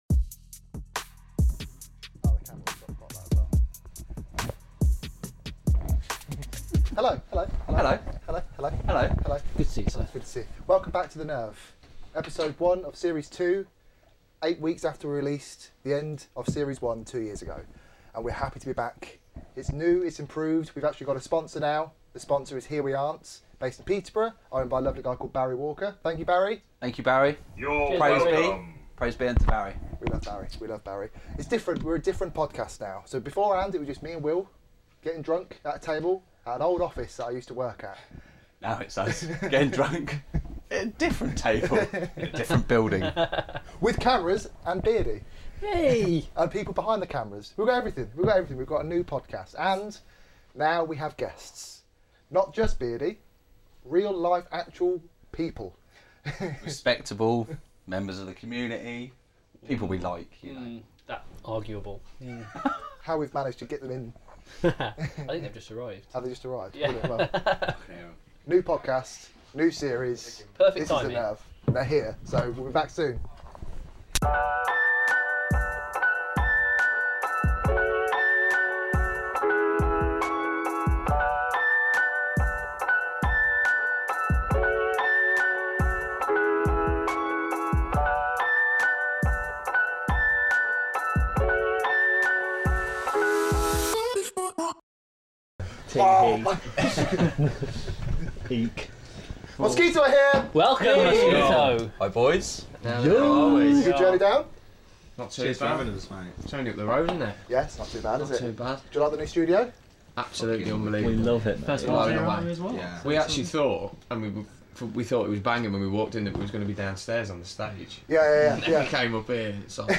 join us in our new studio for our first episode. Prepare to hear a lot about poo.